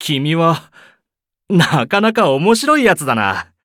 文件 文件历史 文件用途 全域文件用途 Ja_Bhan_fw_01.ogg （Ogg Vorbis声音文件，长度3.6秒，102 kbps，文件大小：46 KB） 源地址:游戏语音 文件历史 点击某个日期/时间查看对应时刻的文件。 日期/时间 缩略图 大小 用户 备注 当前 2018年5月25日 (五) 02:59 3.6秒 （46 KB） 地下城与勇士  （ 留言 | 贡献 ） 分类:巴恩·巴休特 分类:地下城与勇士 源地址:游戏语音 您不可以覆盖此文件。